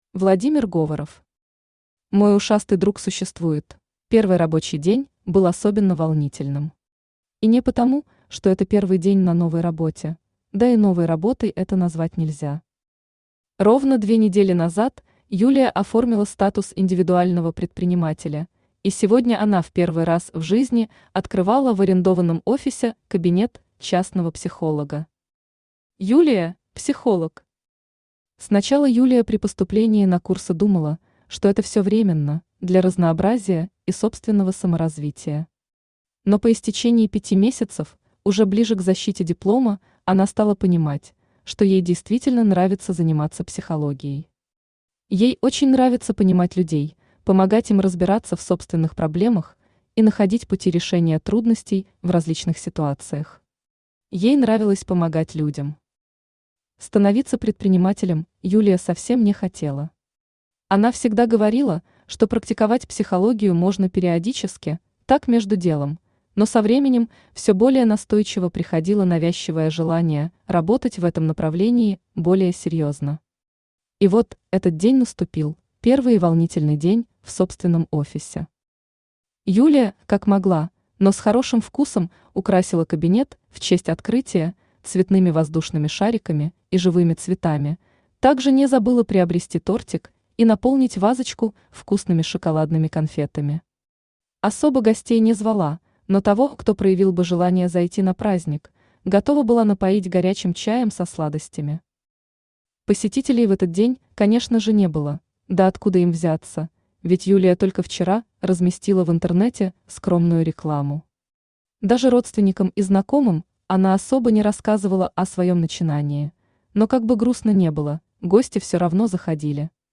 Аудиокнига Мой ушастый друг!
Автор Владимир Говоров Читает аудиокнигу Авточтец ЛитРес.